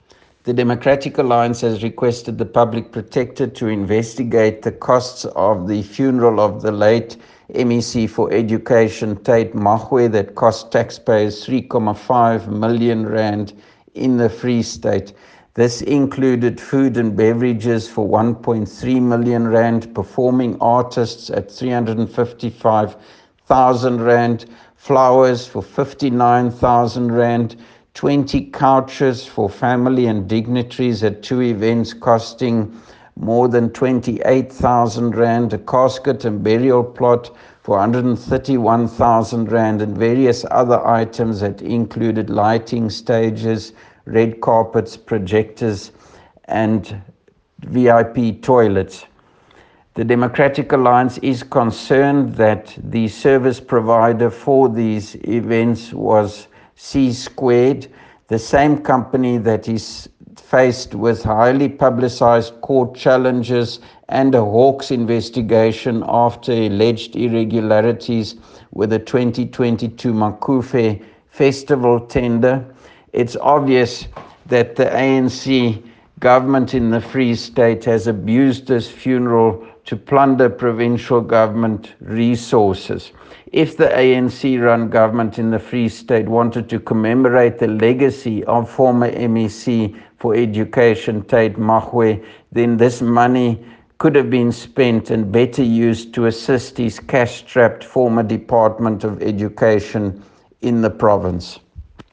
Issued by Roy Jankielsohn – Leader of the Official Opposition in the Free State Legislature
Afrikaans soundbites by Roy Jankielsohn MPL and